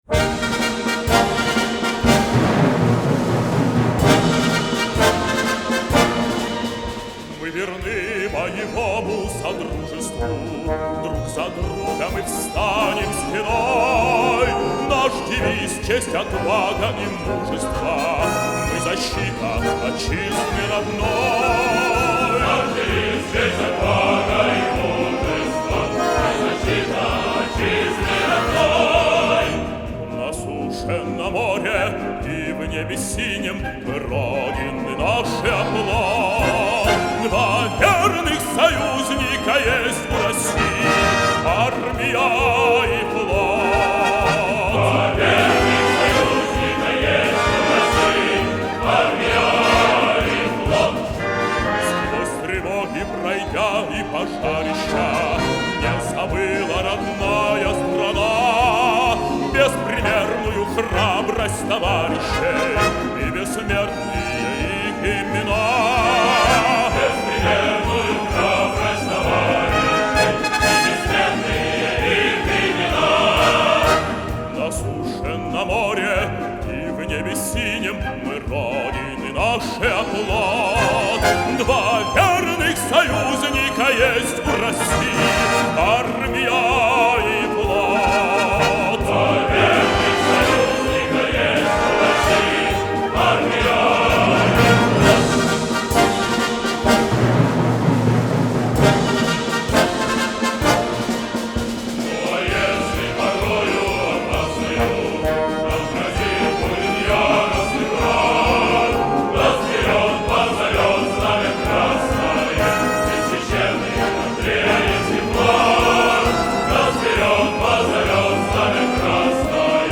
солист